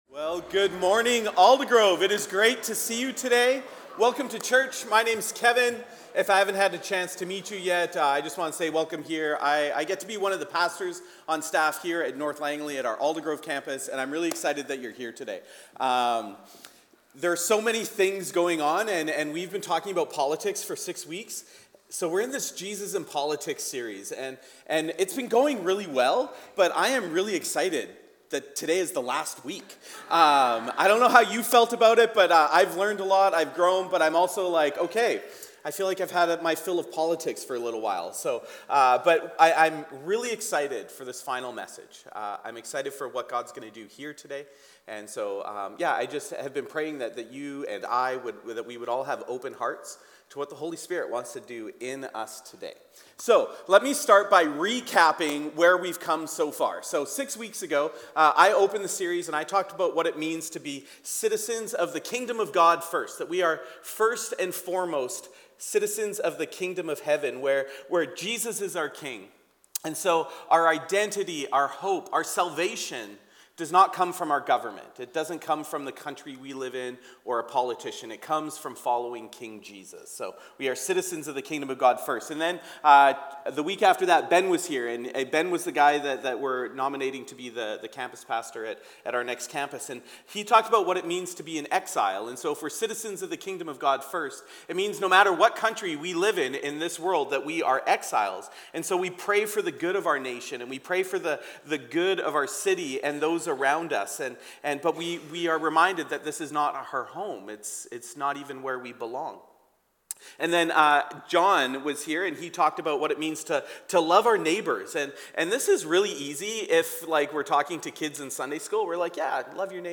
In this sermon, we practice 4 different ways of praying politically.